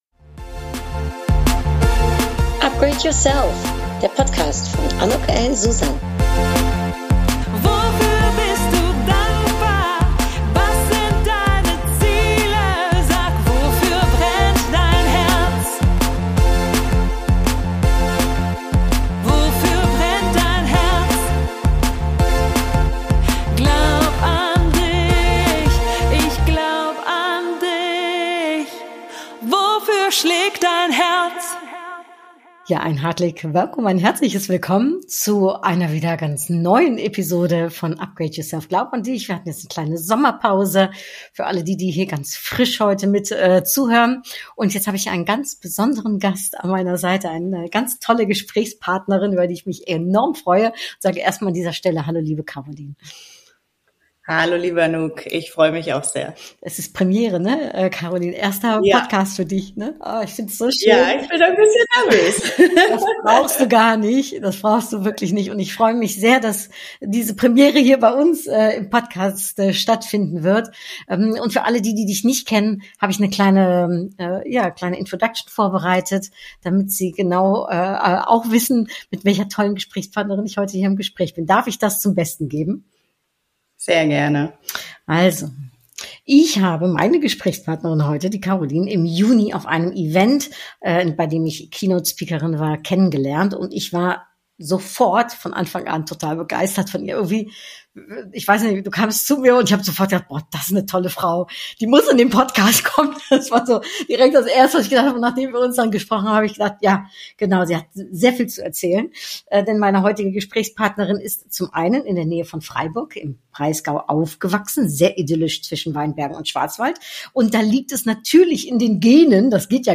Das erste Upgrade Yourself Interview nach den Sommerferien - direkt ein ganz besonderes.